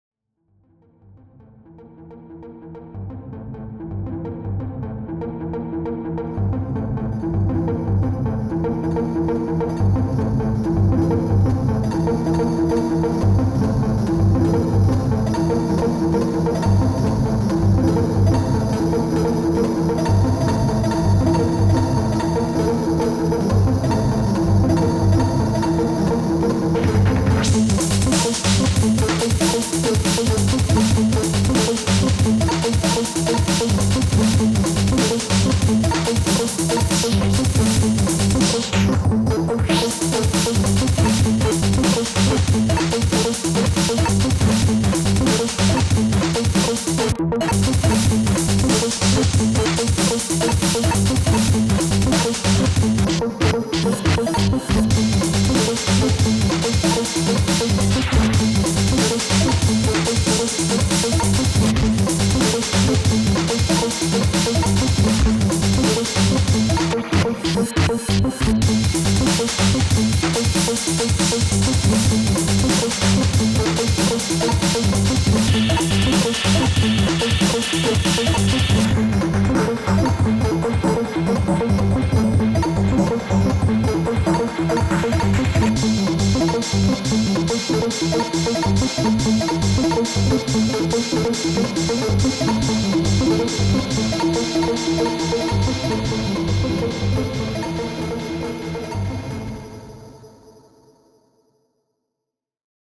I used the Octatrack to sequence and sample a couple of bars from the Dreadbox Hades. Sliced the loop up and used it for bass and lead, then stuck a break underneath for drums. Everything messed about a bit with LFOs and crossfader (over)use and then recorded into Zoom H6.